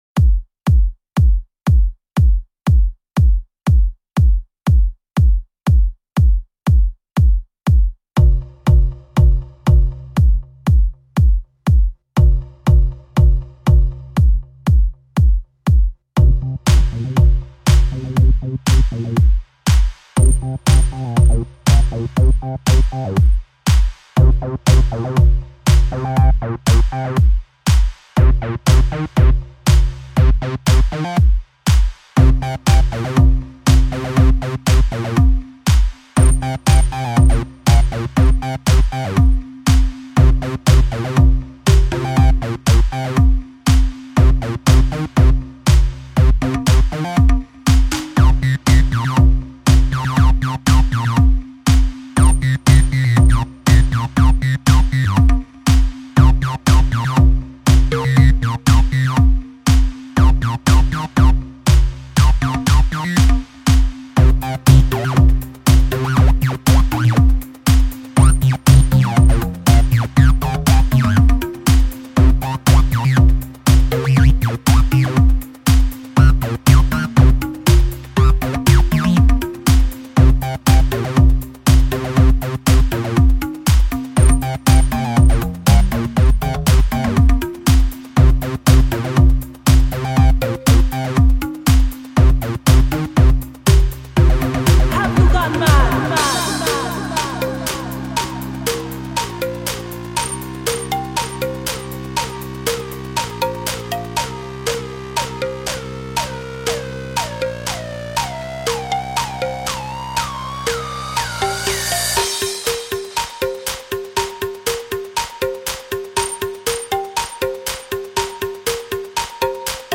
Acid House.